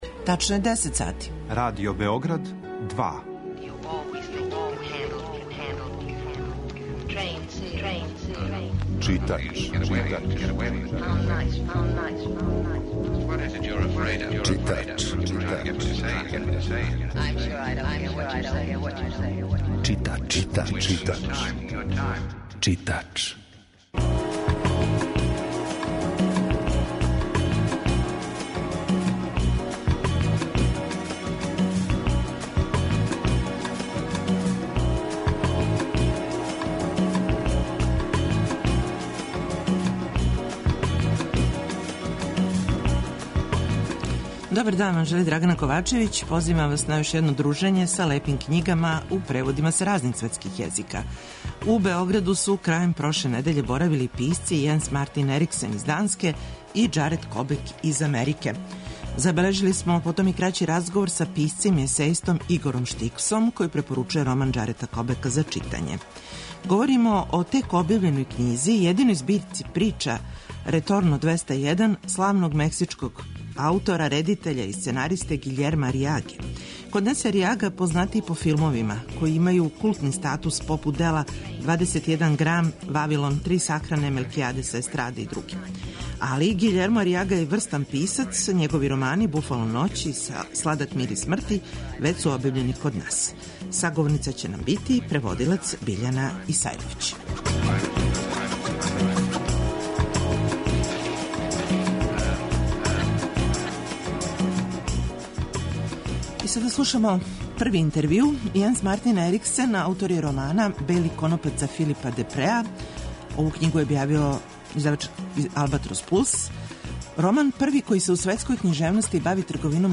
Чућете разговоре са писцима који су ових дана боравили у Београду да би представили нашим читаоцима своје нове књиге.